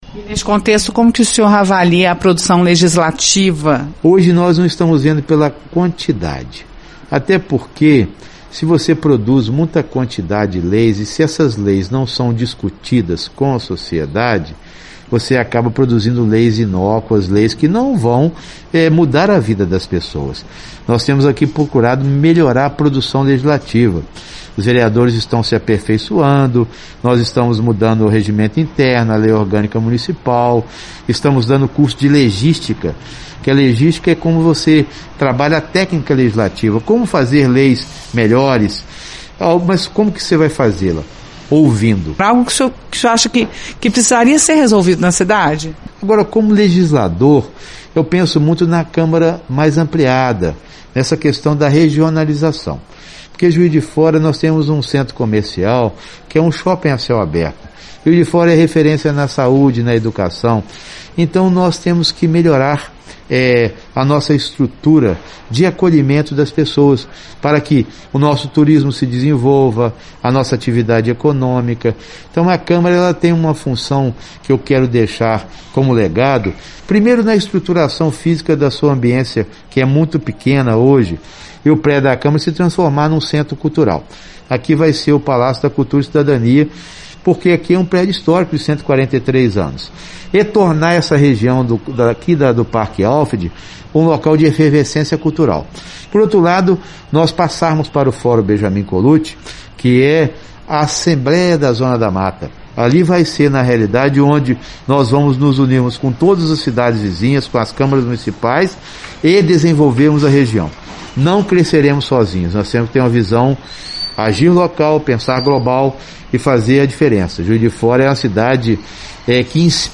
Entrevista: Presidente da Câmara Municipal de JF faz balanço da gestão frente à pandemia
O presidente da Câmara Municipal de Juiz de Fora, Juracy Scheffer (PT), conversa com a reportagem da FM Itatiaia e apresenta balanço sobre a gestão frente à pandemia.